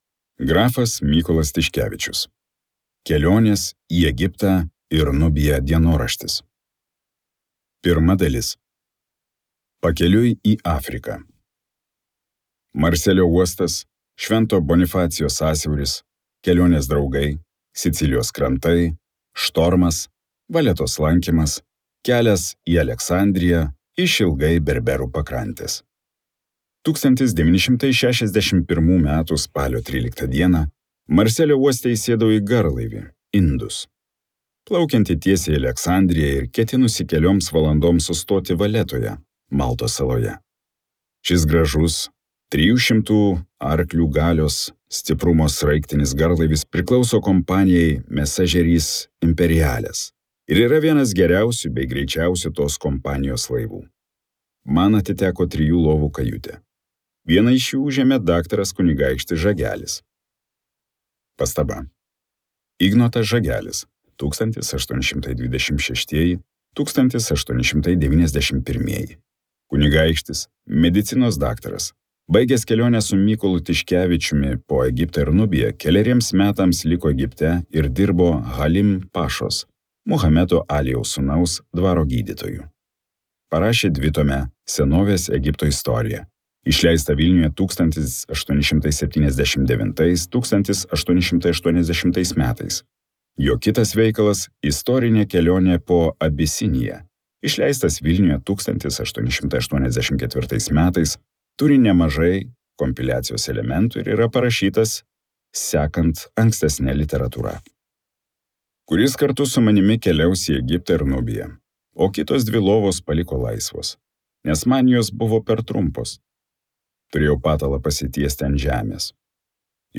Kelionė Nilu. Grafo Mykolo Tiškevičiaus kelionės į Egiptą ir Nubiją dienoraštis, 1861–1862 metai | Audioknygos | baltos lankos